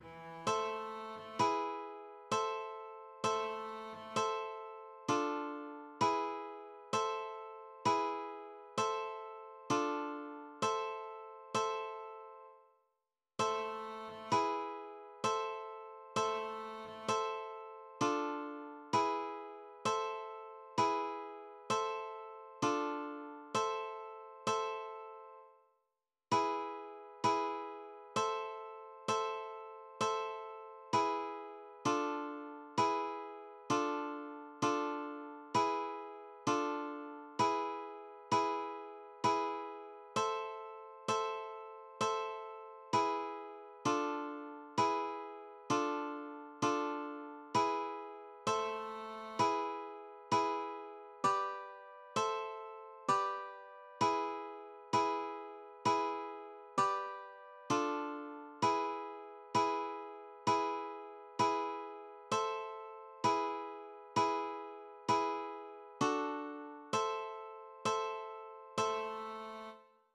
Wiki-Liedermappe